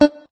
note_beepey_2.ogg